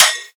• Natural Trap Acoustic Snare Sound D# Key 21.wav
Royality free steel snare drum sample tuned to the D# note.
natural-trap-acoustic-snare-sound-d-sharp-key-21-4cs.wav